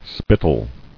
[spit·tle]